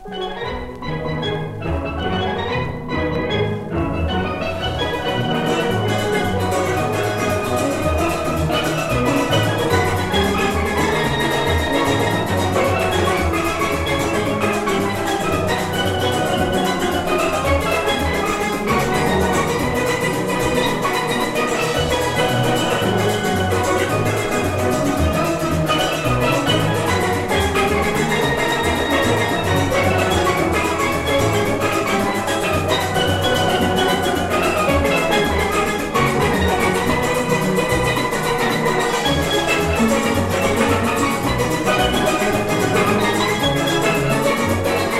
World, Steel band　Trinidad & Tobago　12inchレコード　33rpm　Stereo